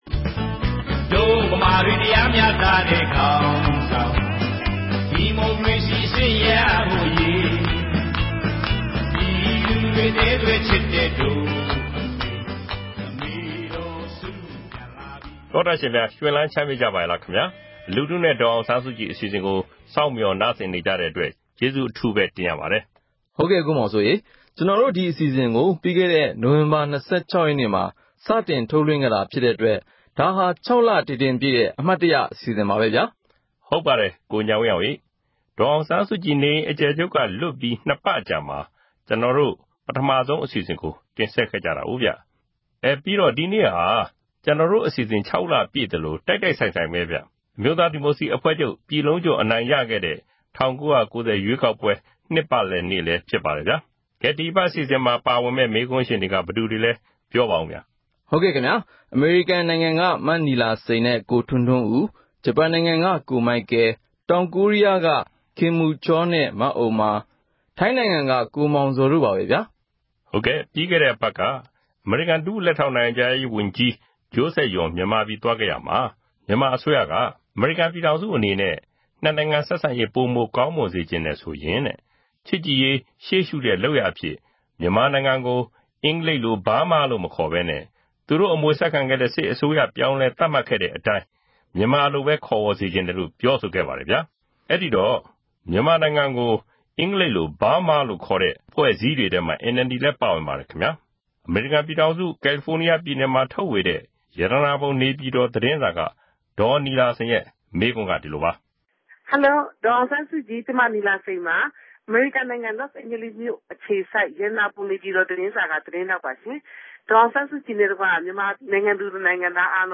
လူထုနဲ့ ဒေါ်အောင်ဆန်းစုကြည် အစီအစဉ်ကို RFA က အပတ်စဉ် သောကြာနေ့ ညတိုင်းနဲ့ ဗုဒ္ဓဟူးနေ့ မနက်တိုင်း တင်ဆက်နေပါတယ်။ ဒီ အစီအစဉ်ကနေ ပြည်သူတွေ သိချင်တဲ့ မေးခွန်းတွေကို ဒေါ်အောင်ဆန်းစုကြည် ကိုယ်တိုင် ဖြေကြားပေးမှာ ဖြစ်ပါတယ်။